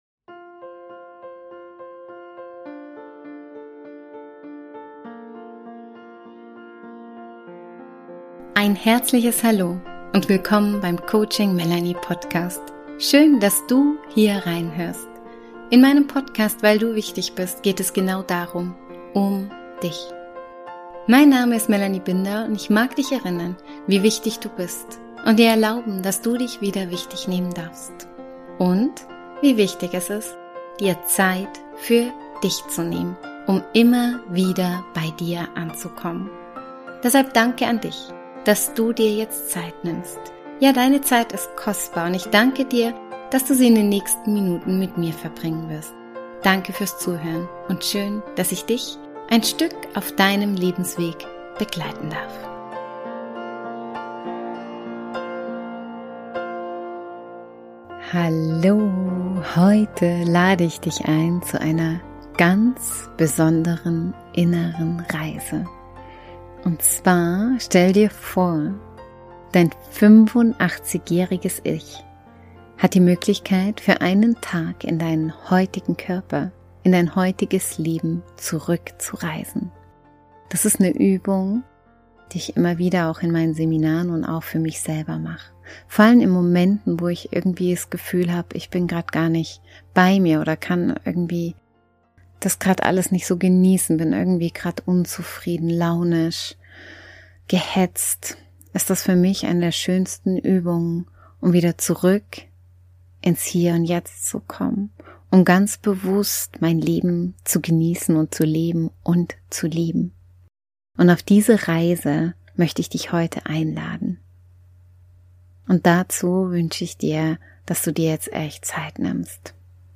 Diese geführte Meditation nimmt dich mit auf eine Reise, die dich tief berührt, dankbar macht und dich daran erinnert, das Leben heute – genau jetzt – in all seiner Fülle zu spüren und zu genießen.